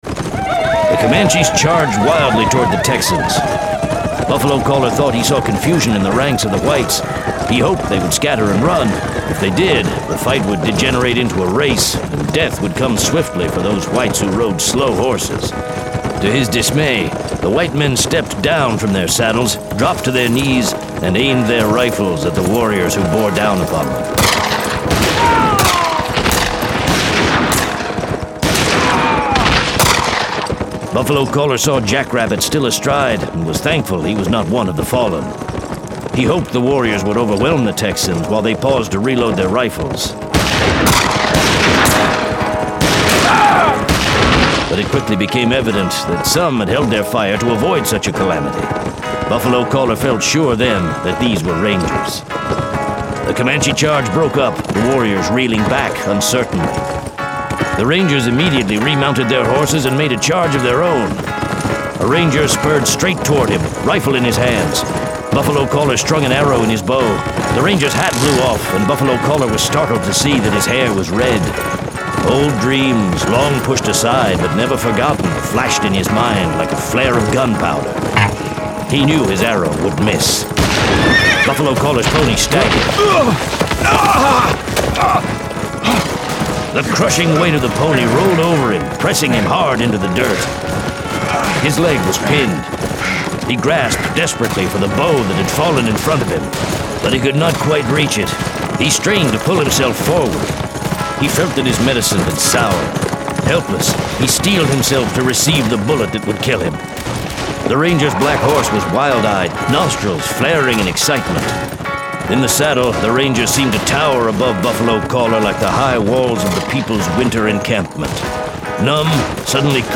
Full Cast. Cinematic Music. Sound Effects.
Genre: Western